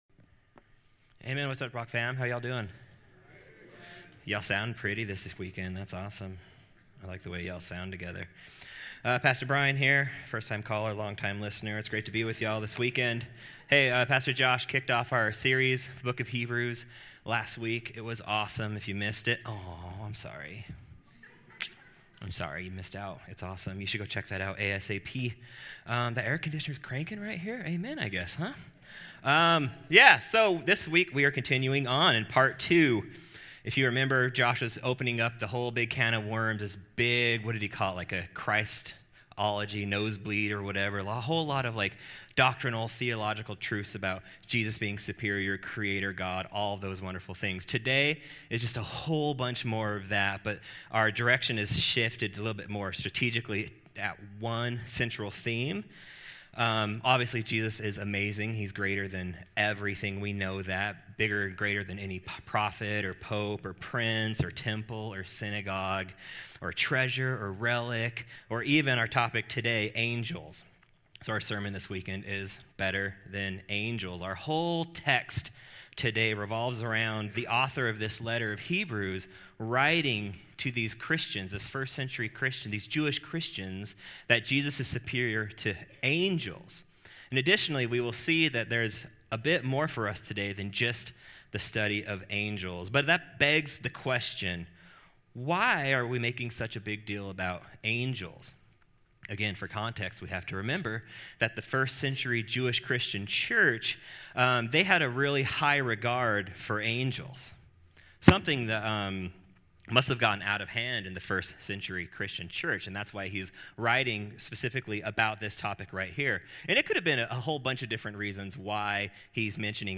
In this message, we will unpack that, as well as our ever pressing need to be students of God's Word.